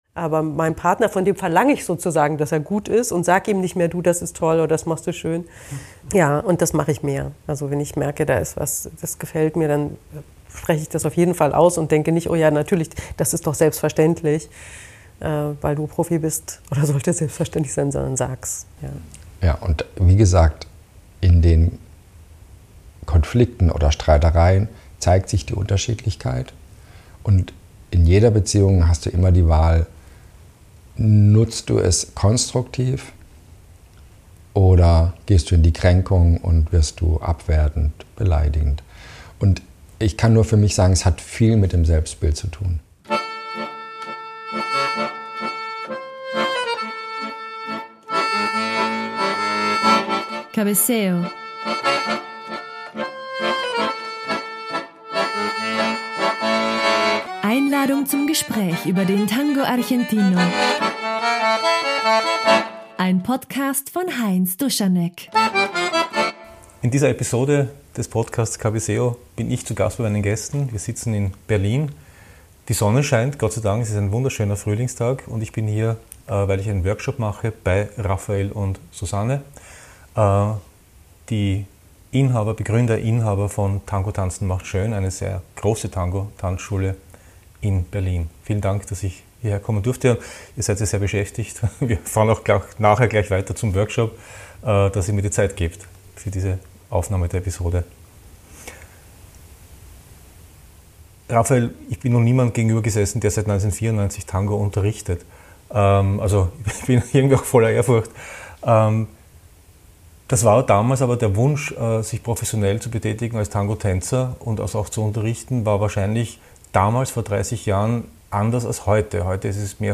Zu Besuch im Studio „Tangotanzen macht schön“ in Berlin-Kreuzberg